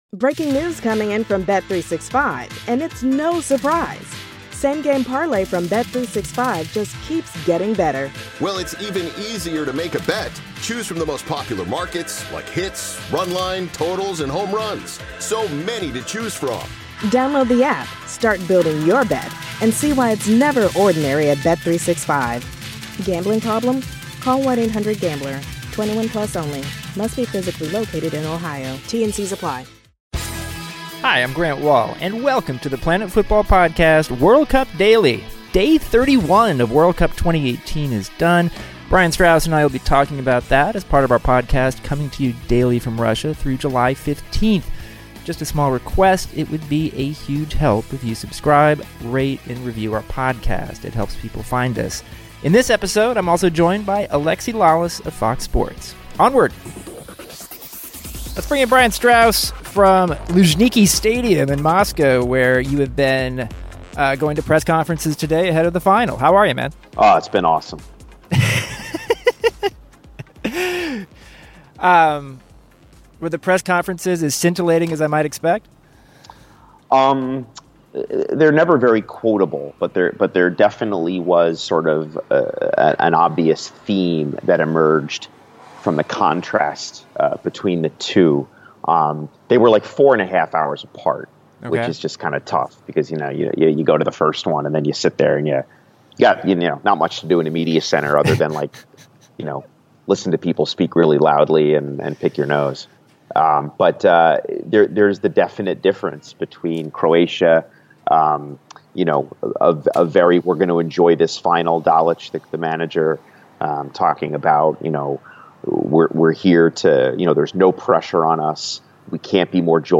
The guys also discuss Belgium's 2-0 win over England in the third-place game. Then Grant interviews Alexi Lalas of Fox Sports and hears his thoughts on Russia 2018.